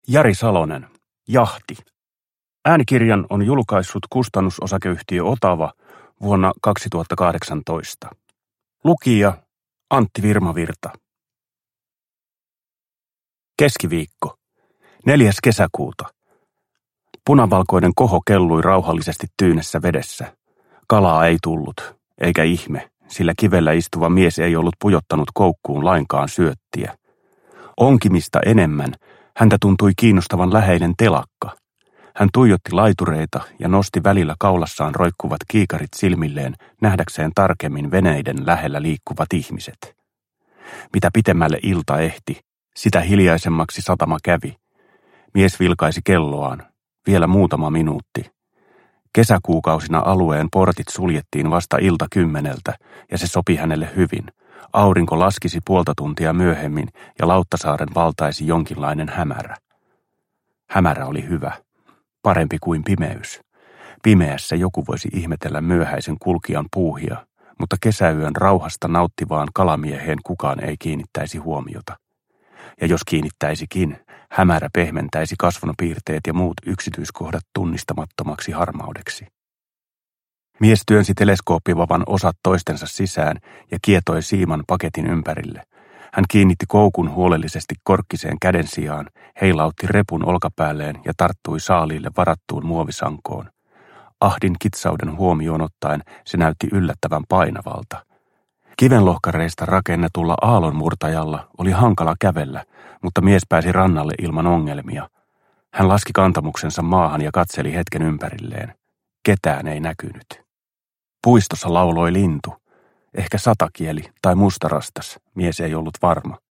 Jahti – Ljudbok – Laddas ner
Uppläsare: Antti Virmavirta